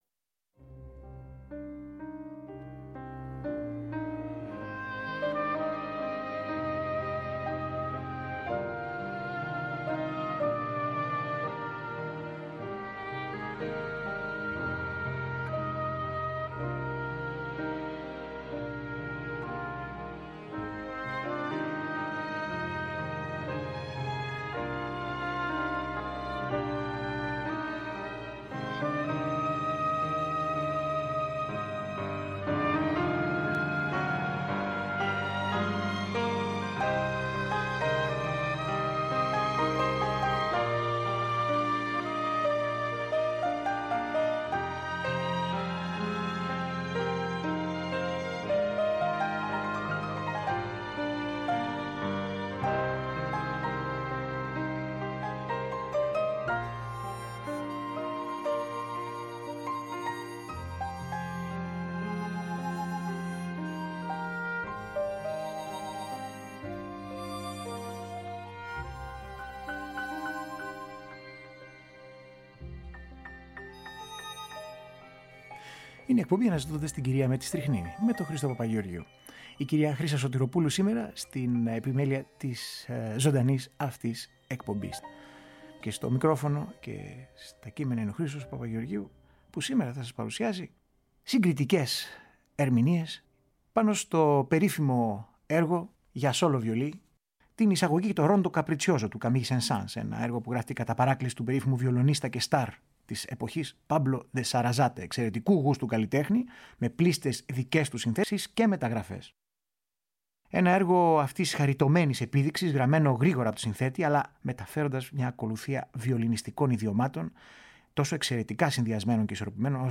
Ακούμε και συγκρίνουμε την Εισαγωγή και Rondo Capriccioso του Camille Saint Saens σε καταπληκτικές ερμηνείες από κορυφαίους εκτελεστές.